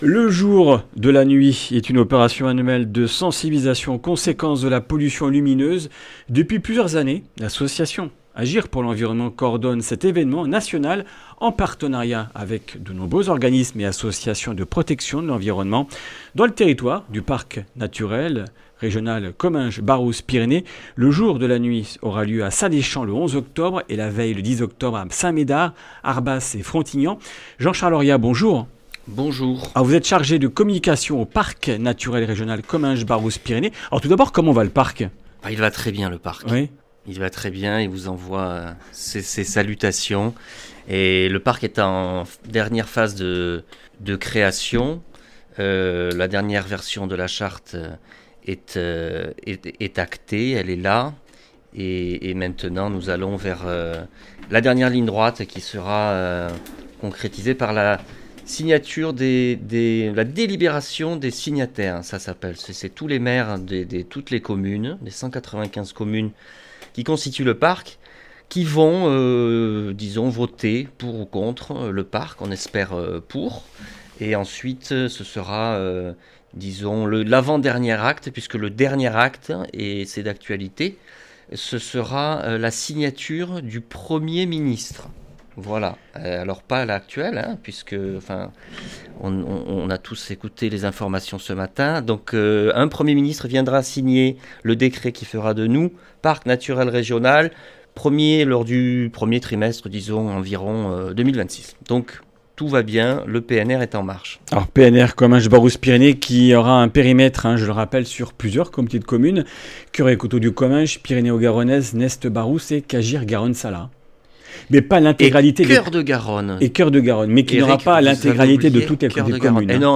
Comminges Interviews du 07 oct.